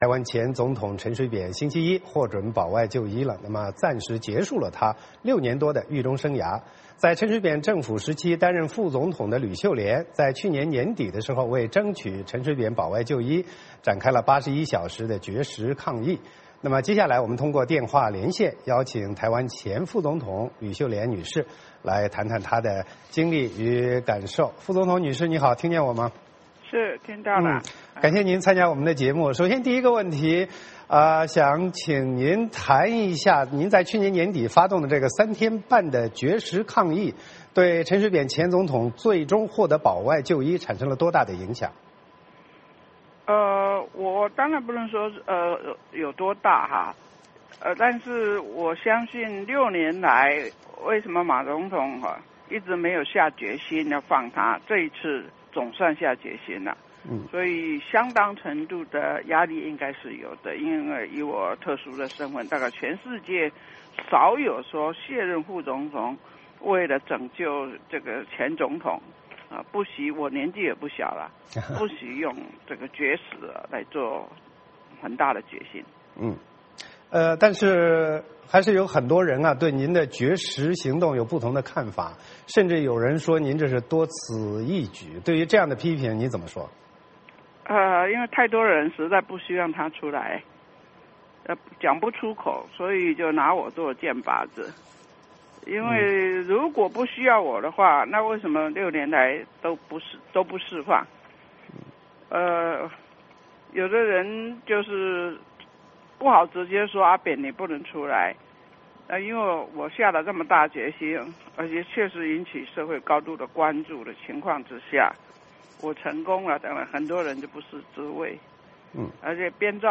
VOA连线：专访台湾前副总统吕秀莲
台湾前总统陈水扁星期一获准保外就医，暂时结束他6年多的狱中生涯。在扁政府时期担任副总统的吕秀莲，去年底为争取陈水扁保外就医，展开了81小时的绝食抗议。今天节目中我们通过电话连线邀请了台湾前副总统吕秀莲来谈谈她的经历与感受。